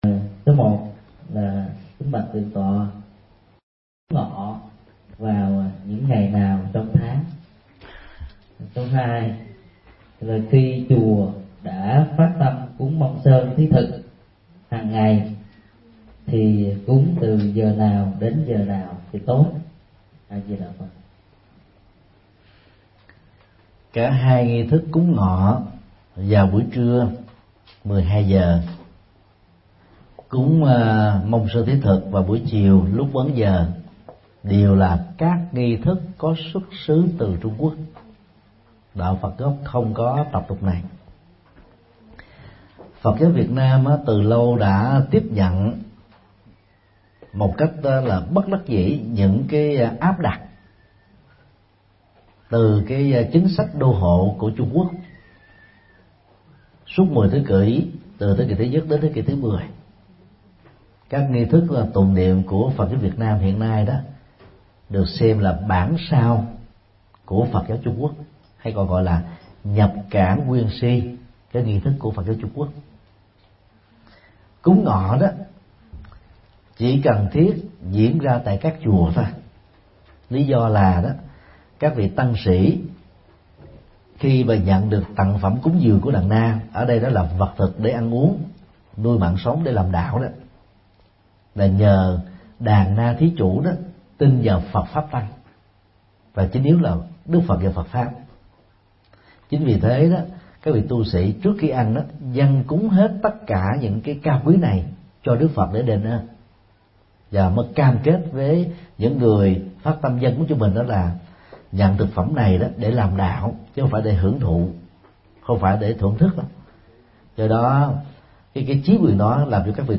Vấn đáp: Cúng ngọ và cúng mông sơn thí thực